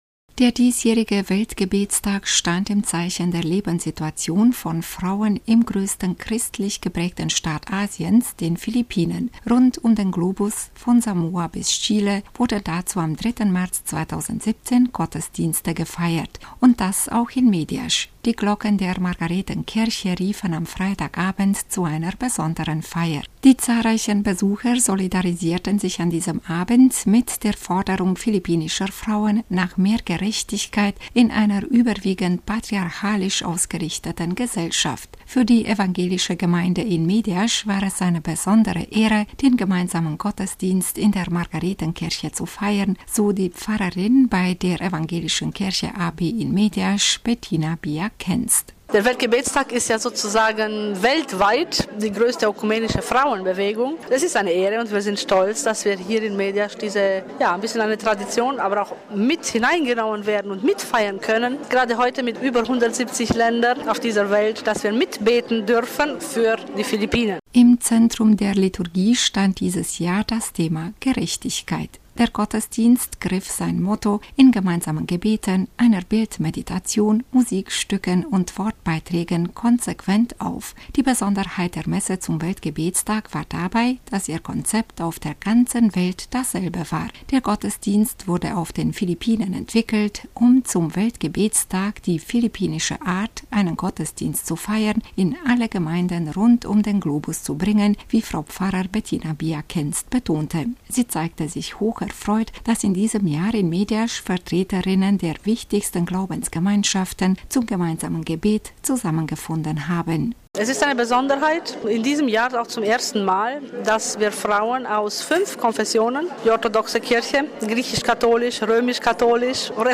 Auch in Mediasch veranstalteten Vertreterinnen der fünf wichtigsten Glaubensrichtungen gemeinsam den Weltgebetstag in der evangelischen Margarethenkirche.